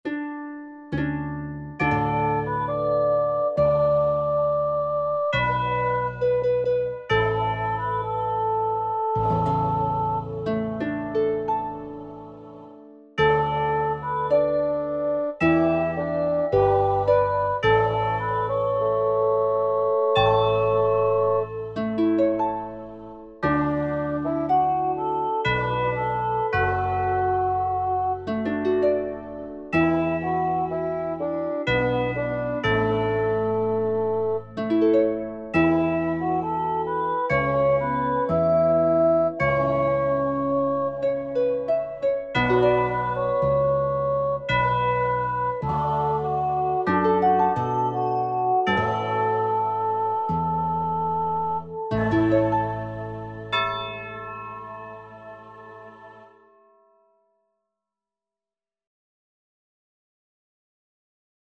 曲目一覧　作曲・編曲 by 加藤徹(C)2009
以下は、加藤がパソコンソフト(スコアメーカーFX3)で作った「カラオケ」です。
※以上、MIDI音源。
chorus   こちら